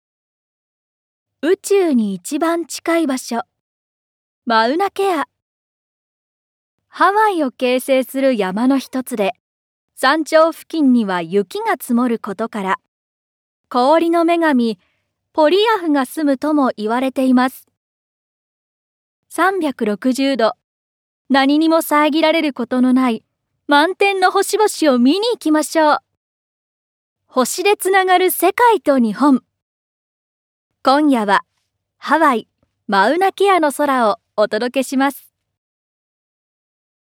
◆ヒーリング番組ナレーション◆